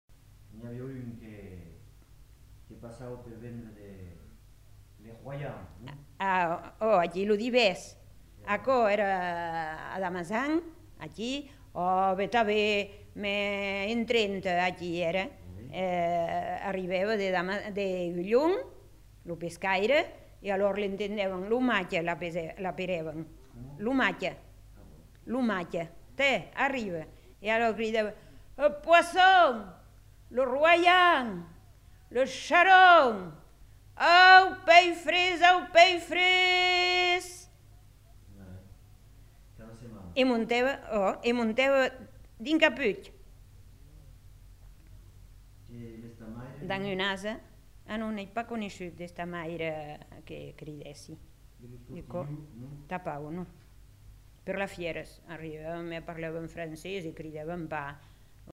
Cri de métier du pêcheur